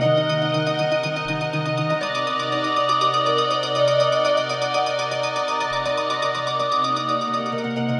Crying_120_D#.wav